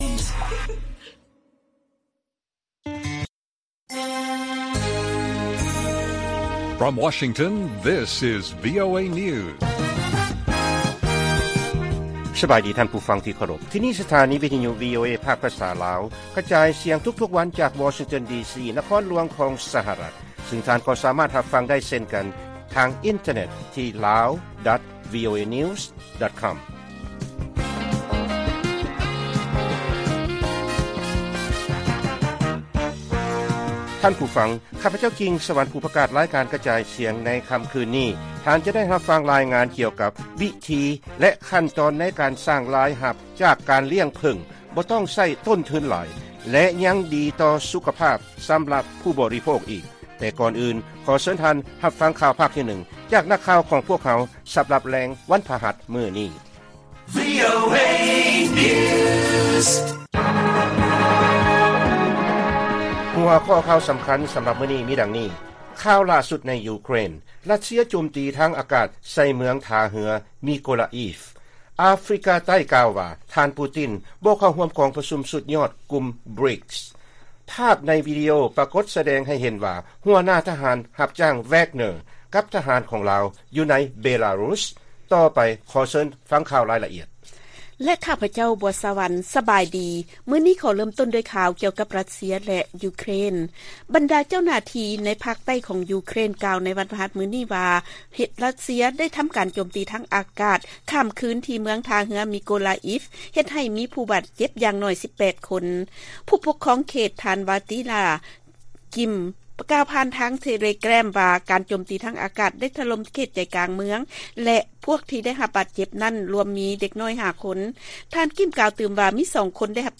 ລາຍການກະຈາຍສຽງຂອງວີໂອເອ ລາວ: ຫຼ້າສຸດໃນຢູເຄຣນ: ຣັດເຊຍໂຈມຕີທາງອາກາດໃສ່ເມືອງ ທ່າເຮືອມິໂກລາອິຟ